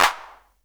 Clap OS 02.wav